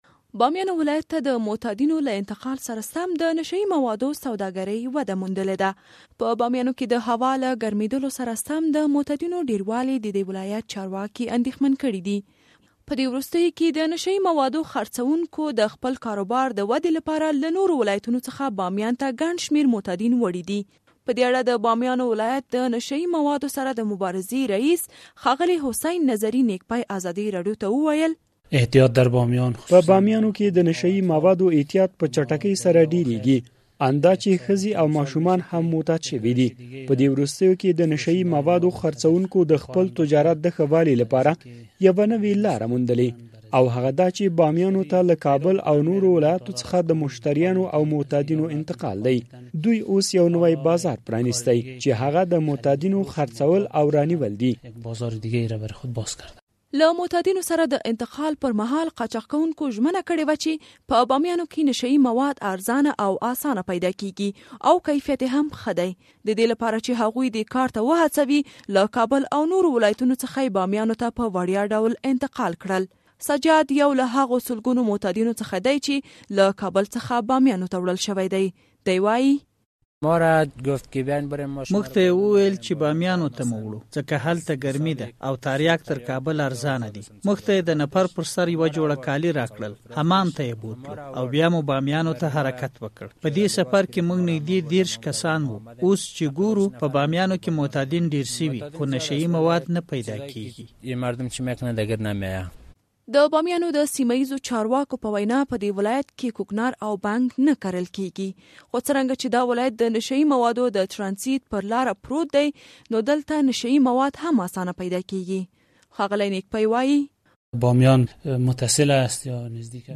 د بامیان راپور